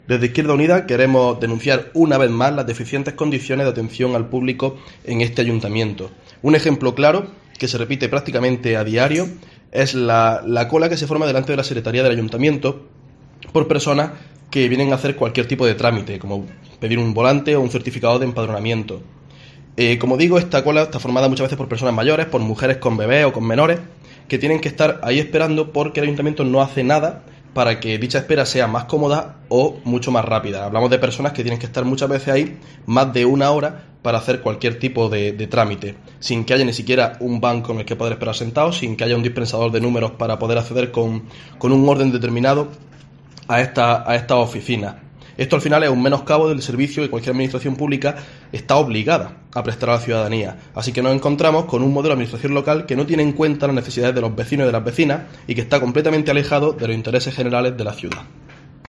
AUDIO: IU vuelve a denunciar la atención que se da al público en el Ayuntamiento de Motril, con colas interminables . Christian Linares, concejal.